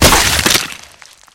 crack11.mp3.wav